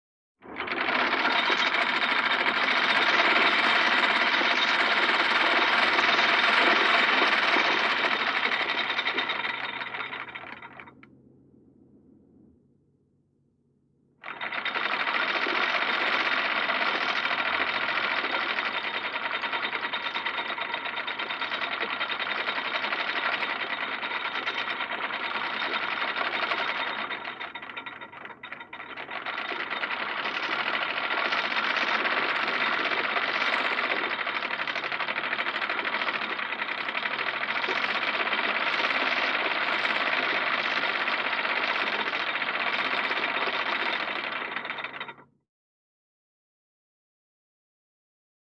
Звук опускания и поднятия якоря на цепи